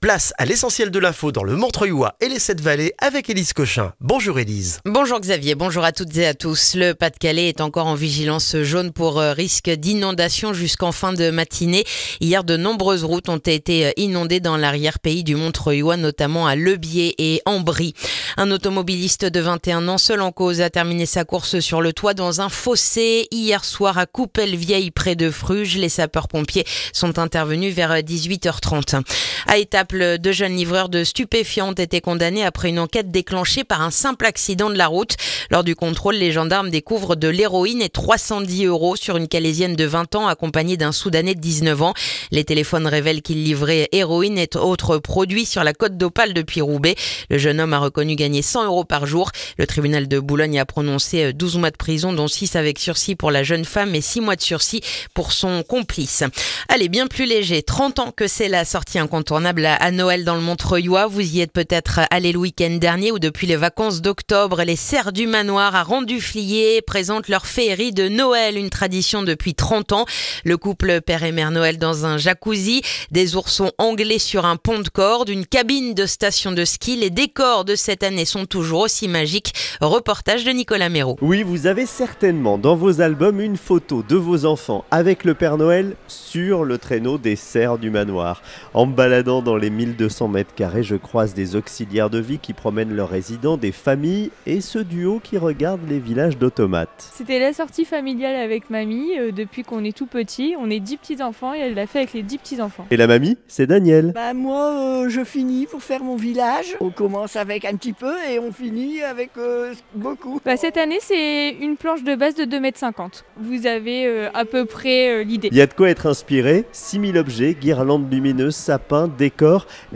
Le journal du mardi 25 novembre dans le montreuillois